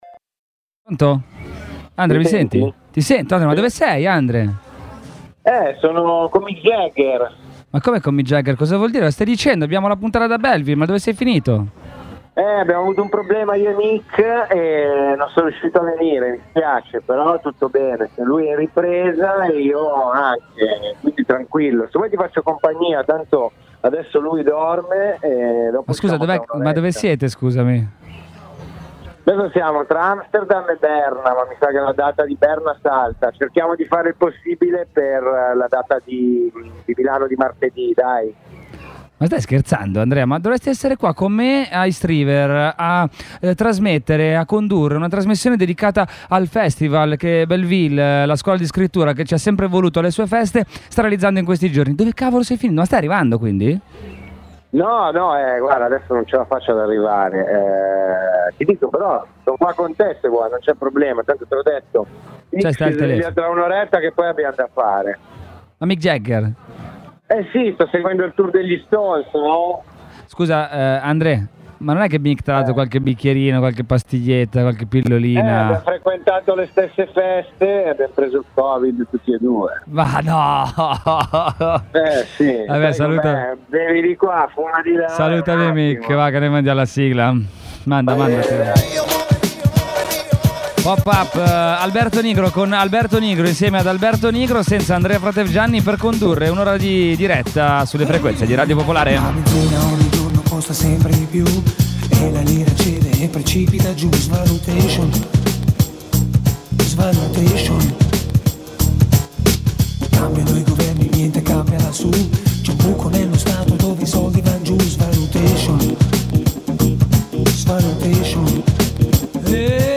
daranno vita a una trasmissione itinerante con uno studio mobile a bordo di un camper che vi accompagnerà in giro per Milano ogni sabato dalle 18.30 alle 19.30. Download